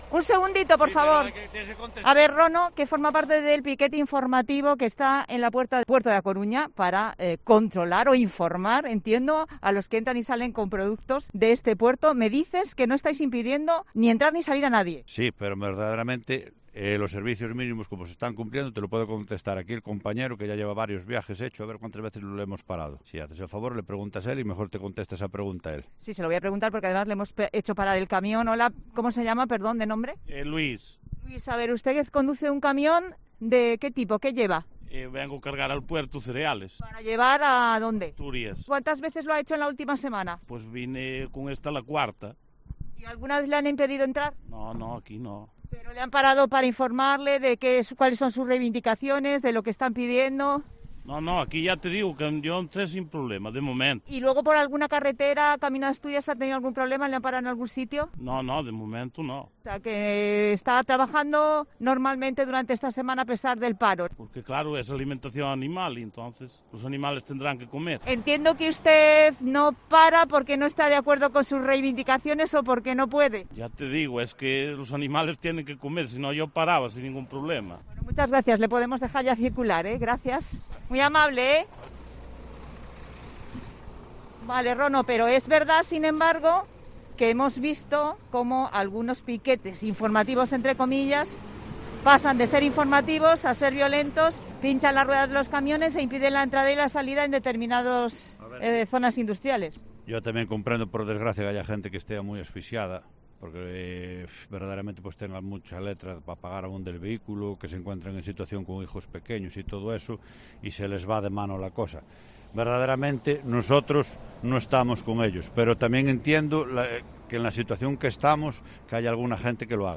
Desde Galicia, cuentan en 'La Tarde' esta noticia desde sus diferentes perspectivas. Ambos se han acercado, entre otros puntos, a La Coruña y Pontevedra para hablar con ganaderos, agricultores y pescaderos para medir las consecuencias de los paros para estos sectores.